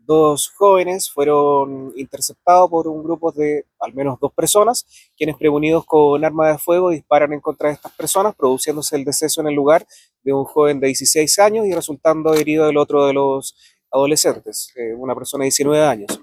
El fiscal ECOH, Francisco Morales, señaló que serían dos los atacantes.